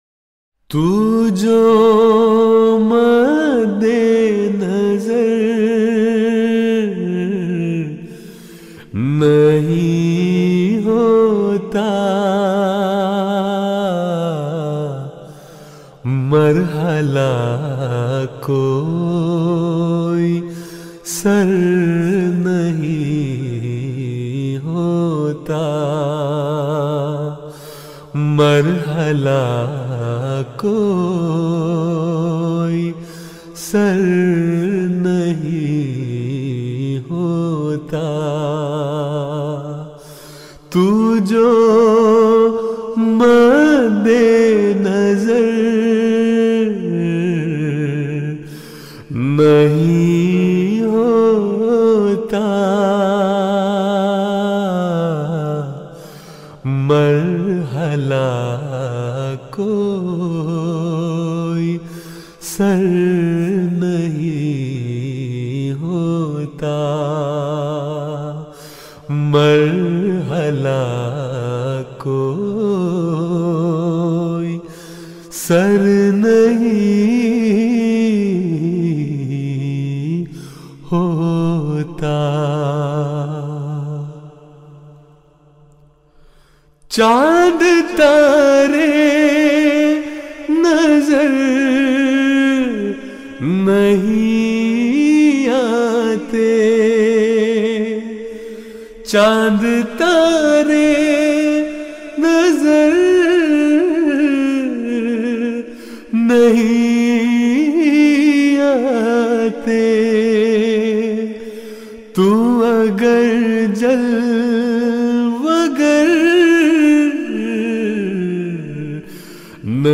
Urdu Poems) متفرق-شعراء پلے لسٹ Playlist تو جو مد نظر نہیں ہوتا Tu Jo Madde Nazar Nahi Hota کلام محمد طاہر ندیم Poem by Muhammad Tahir Nadeem آواز